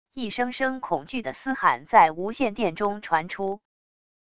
一声声恐惧的嘶喊在无线电中传出wav音频